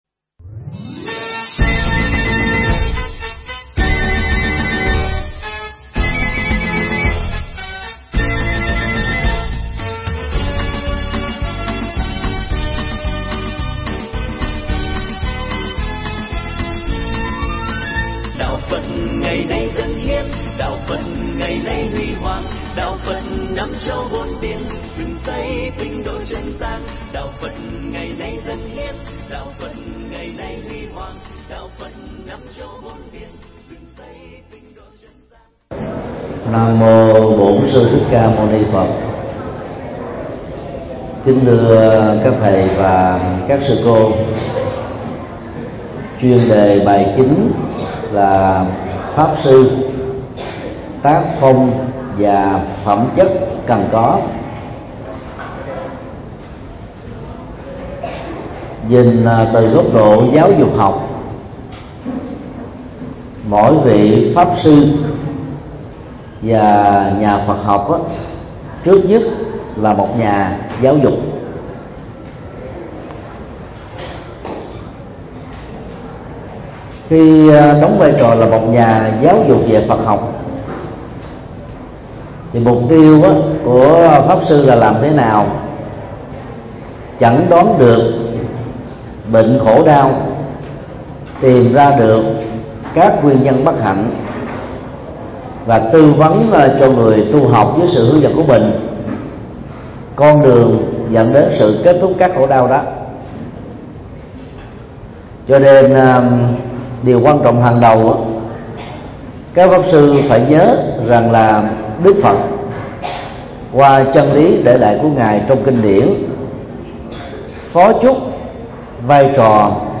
Mp3 Pháp Thoại Sư phạm hoằng pháp 09: Pháp sư: Tác phong và phẩm chất cần có – Thầy Thích Nhật Từ Giảng tại Học viện Phật giáo Việt Nam tại TP. HCM,